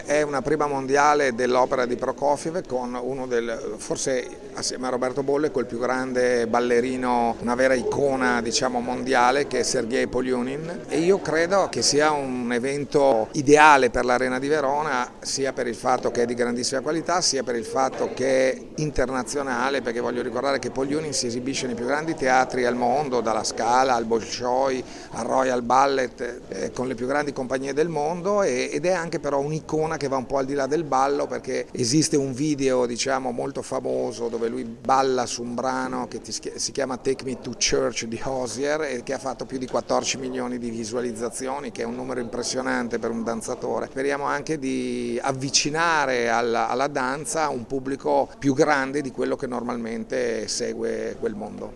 Al microfono della nostra corrispondente